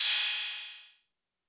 Hats & Cymbals
Cymbal_02.wav